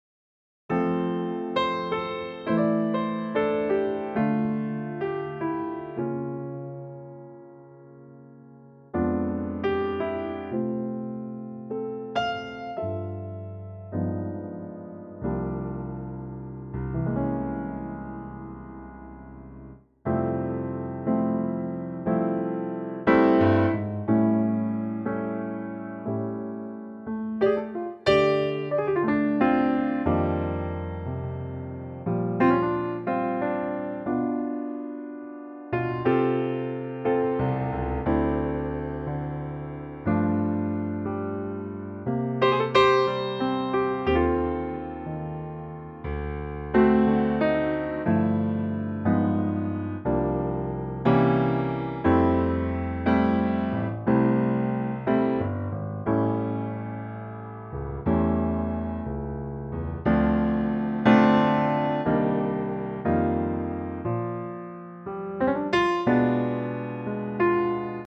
gorgeous piano only arrangement.